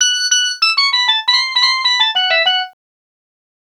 Swinging 60s 5 Organ Lk-F#.wav